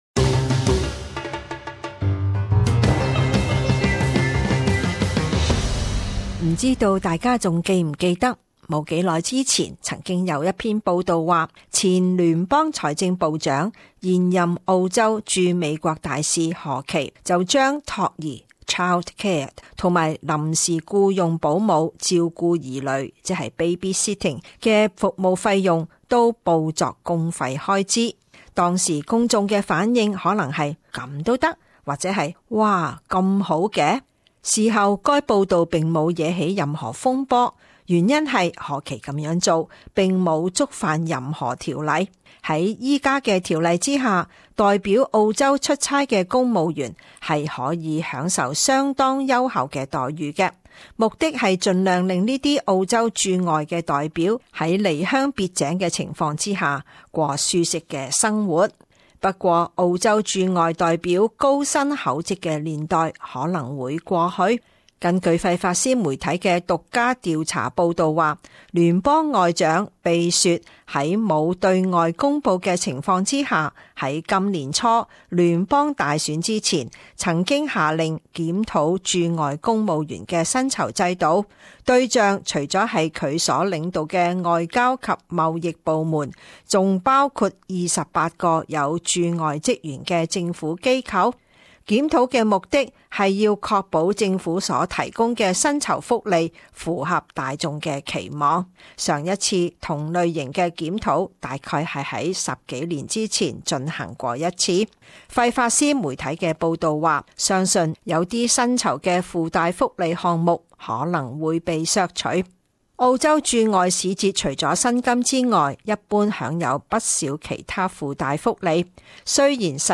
【時事報導】 庇雪檢討駐外公務員薪酬福利